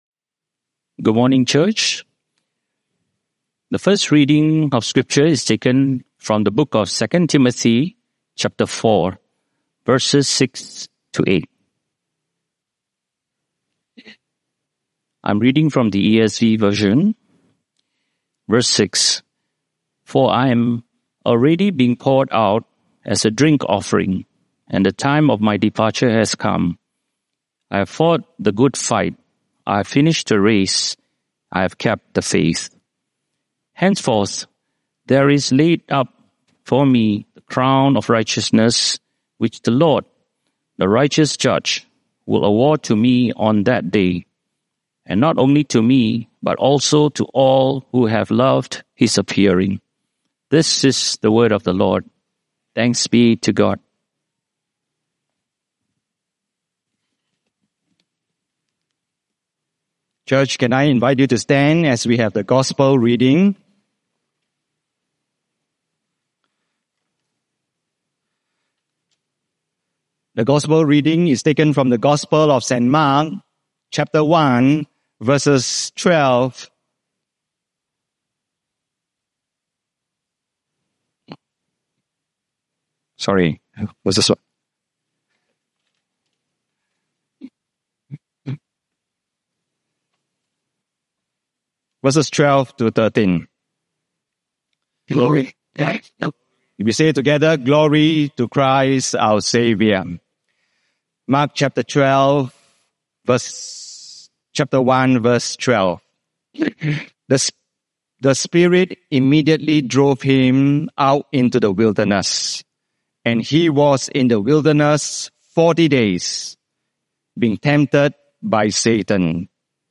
Sermon Archives - St.John's-St.Margaret's Church